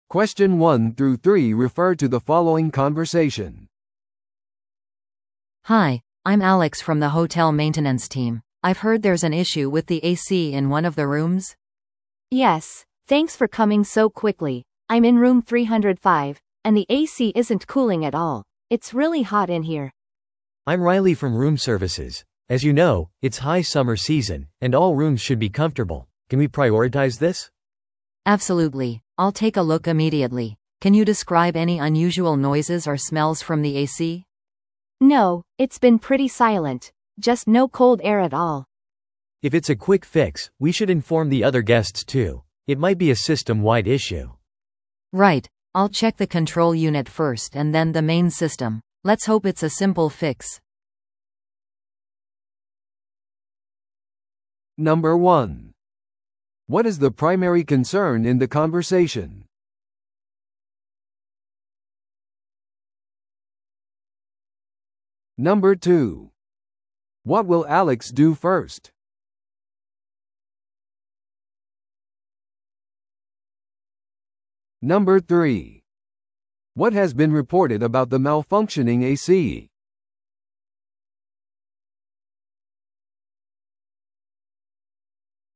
TOEICⓇ対策 Part 3｜ホテルの客室内エアコン不具合に関する会話 – 音声付き No.236